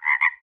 Frog.ogg